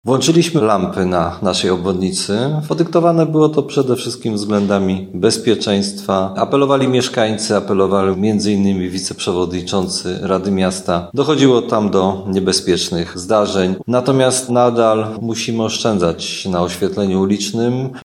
Tłumaczy prezydent Tarnobrzega Dariusz Bożek.